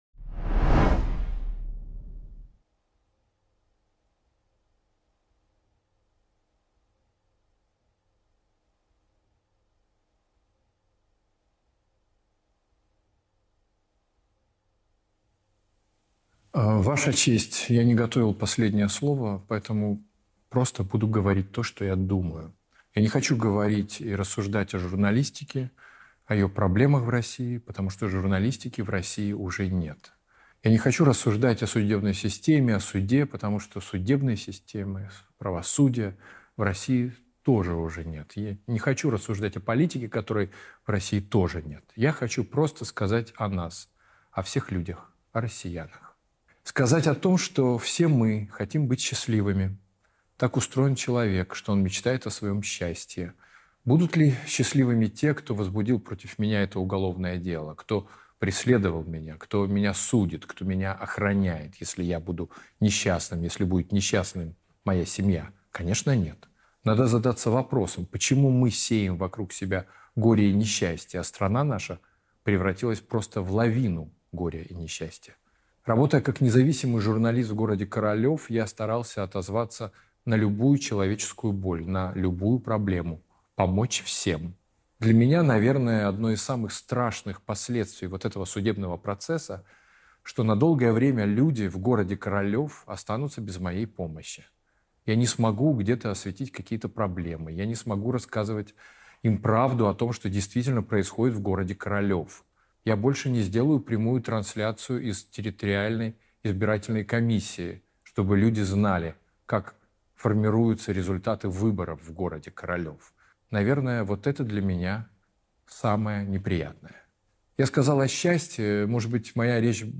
Читает Кирилл Серебренников